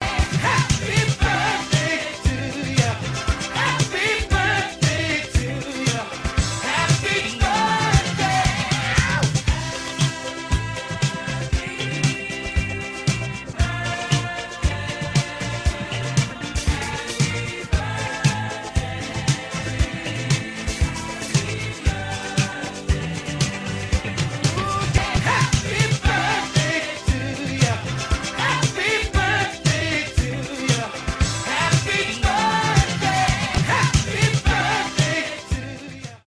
audio (in C) / audio of piano part